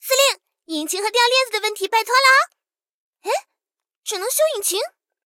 SU-76中破修理语音.OGG